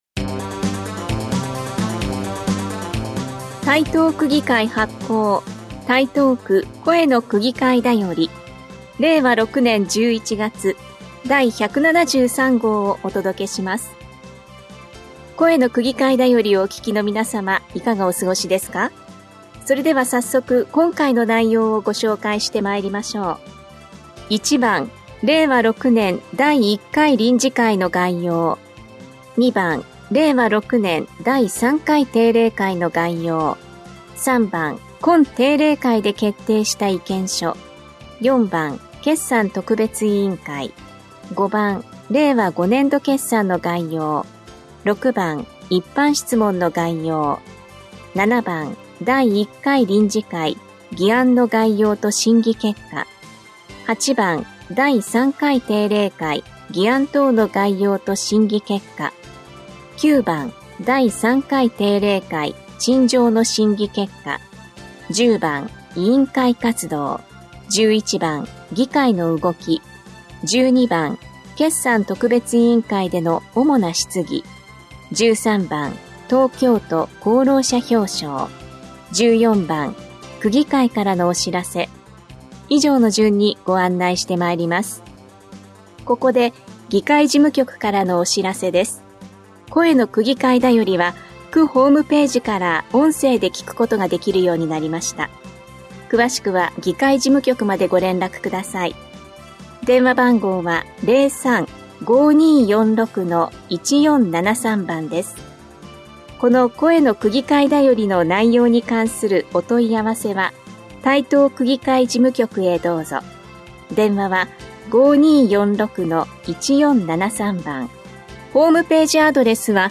声の区議会だよりの音声読み上げデータです。